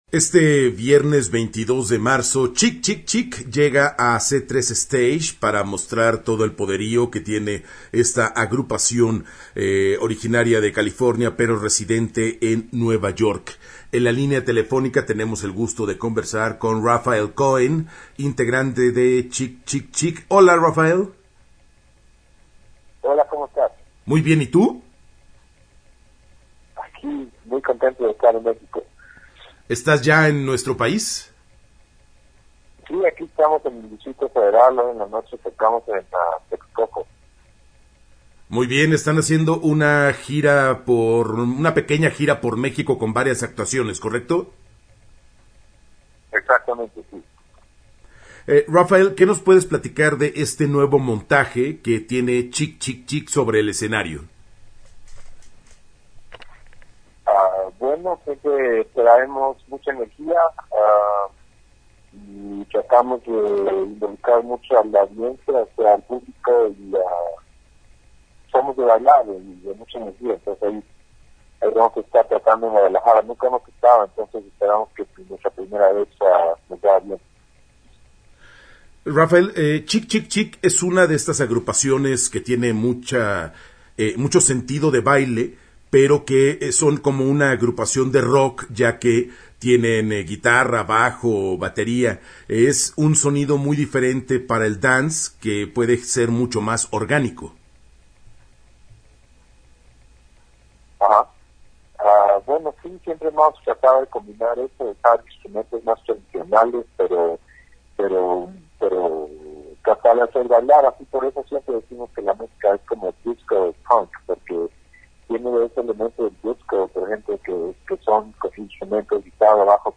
Entrevista-chk-chk-chk-C3-Stage-2019-web.mp3